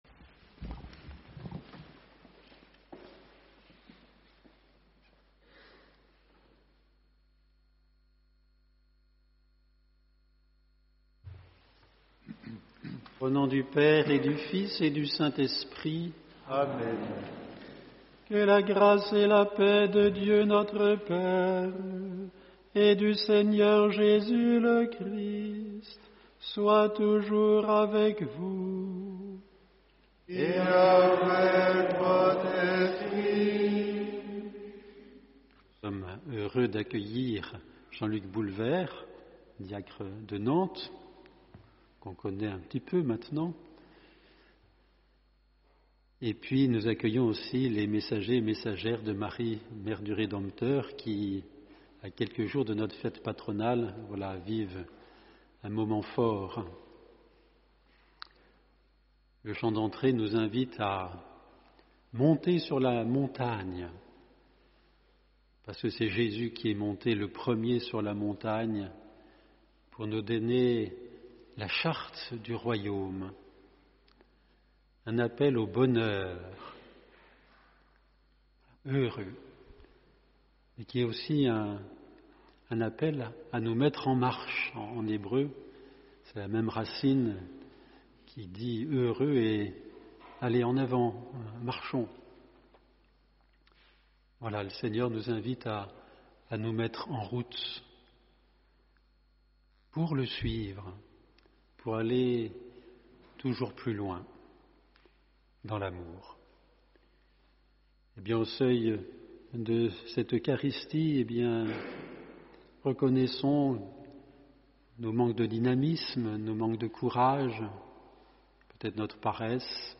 HOMELIE